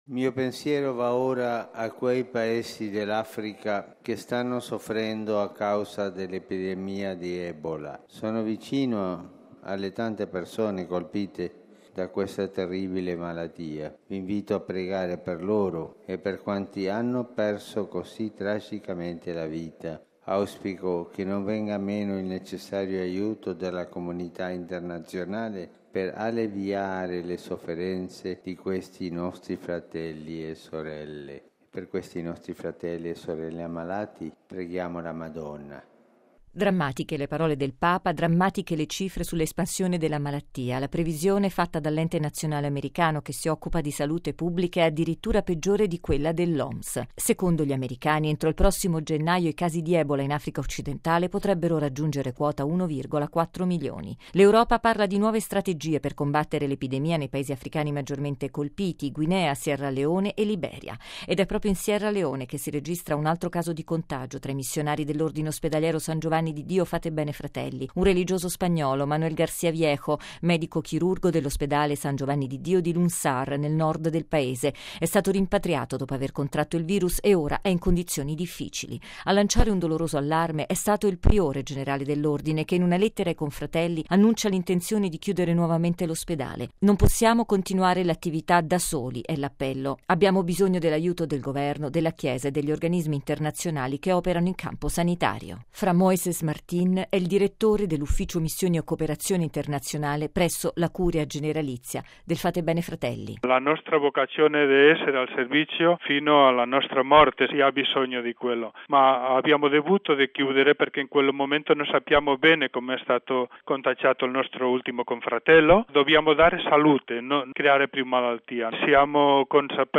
La tragedia di Ebola nelle parole del Papa che, a conclusione dell’udienza generale, ha lanciato un appello affinché il mondo guardi con attenzione alle conseguenze della devastante epidemia.